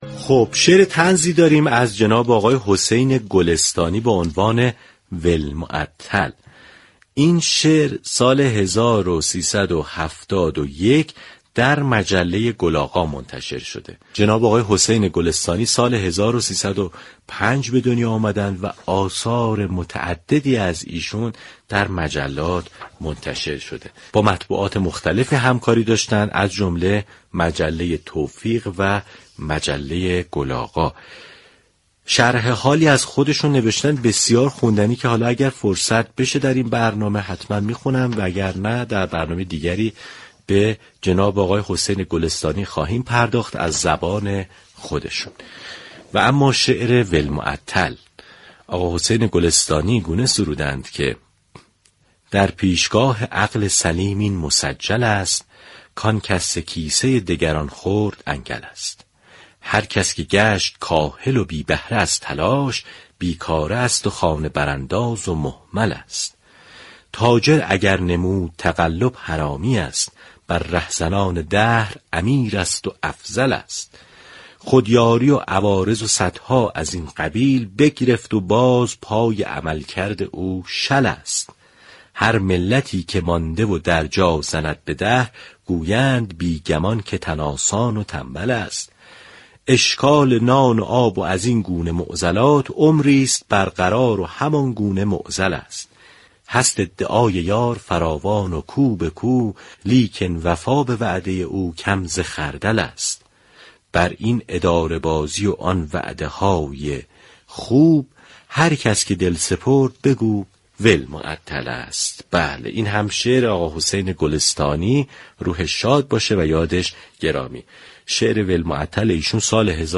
شعر طنز